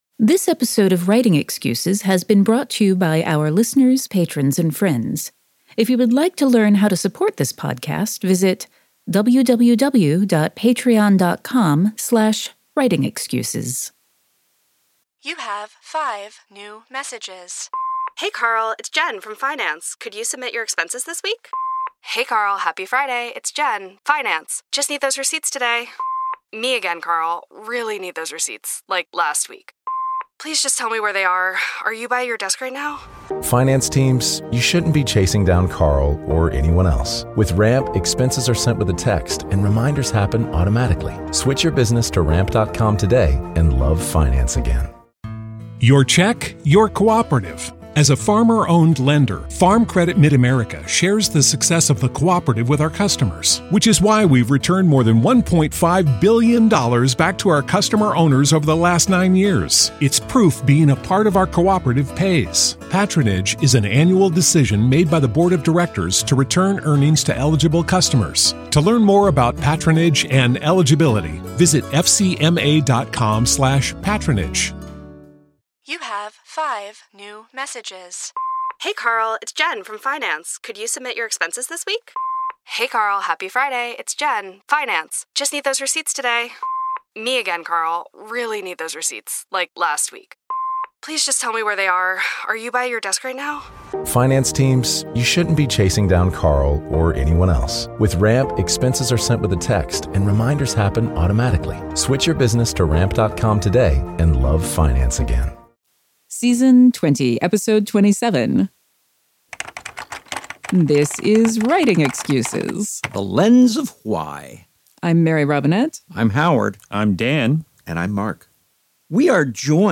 This episode was recorded live at our 2024 Writing Excuses Cruise.